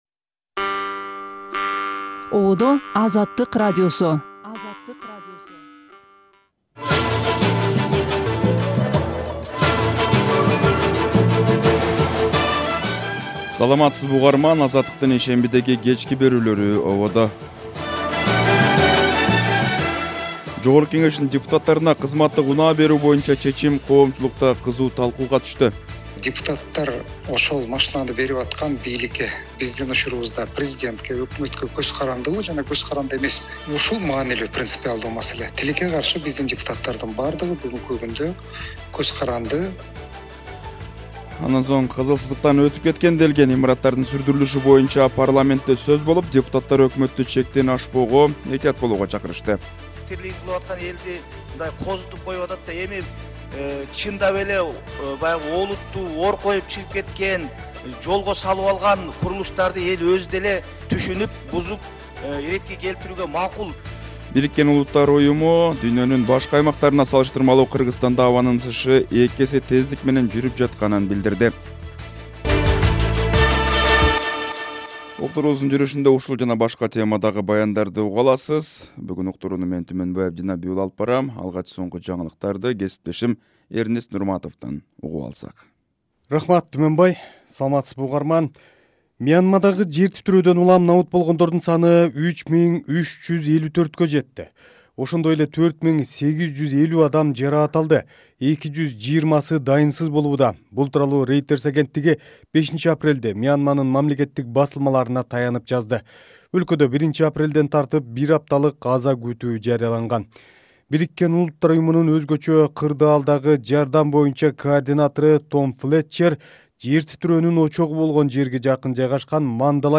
Кечки радио эфир | 05.04.2025| Орус күчтөрүнүн Кривой Рогго соккусунан кеминде 19 киши каза болду